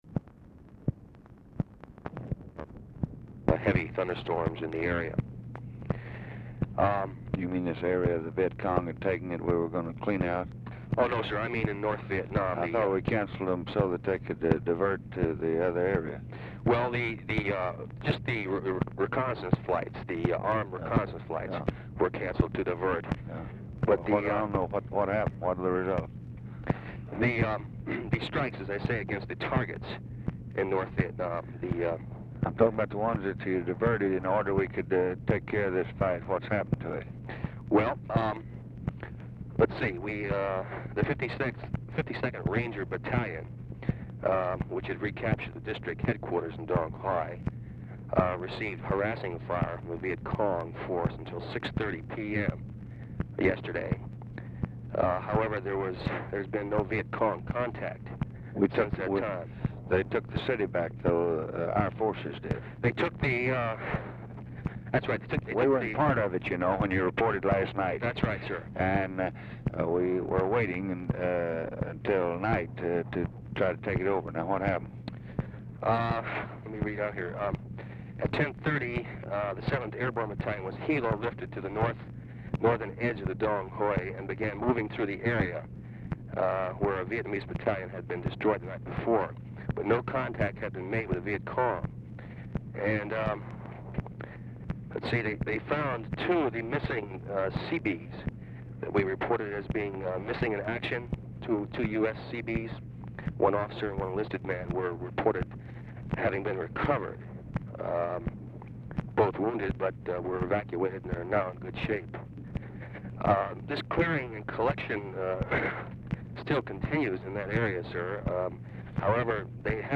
RECORDING STARTS AFTER CONVERSATION HAS BEGUN AND ENDS BEFORE IT IS OVER
Format Dictation belt
Specific Item Type Telephone conversation Subject Defense Vietnam